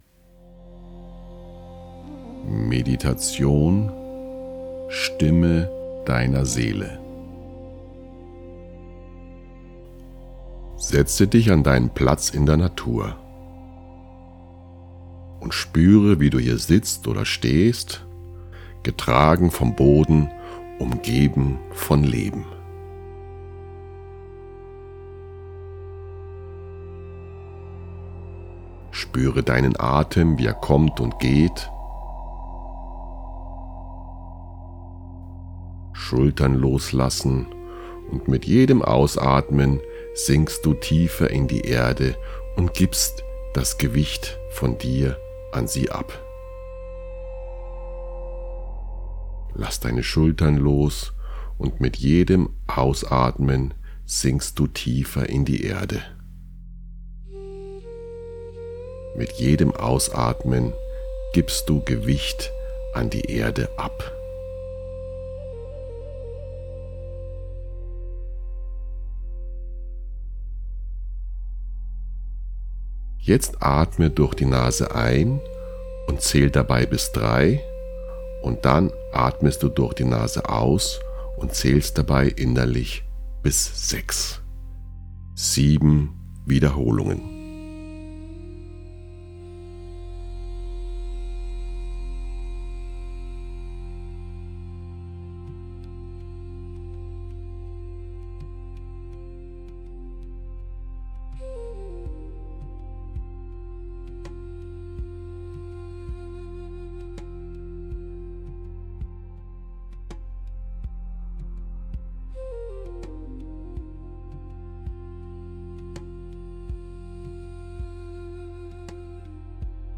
schlicht, ruhig, ohne Bilderflut oder innere Reise.
Meditation-Stimme-deiner-Seele_mit_Effekten-und-Sound.mp3